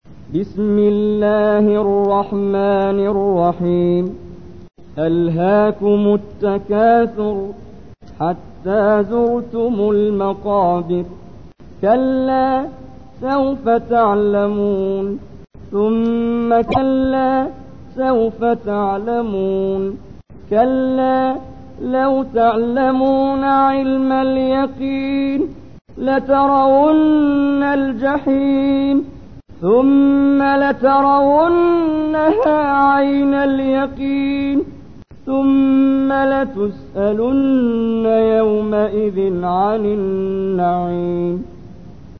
تحميل : 102. سورة التكاثر / القارئ محمد جبريل / القرآن الكريم / موقع يا حسين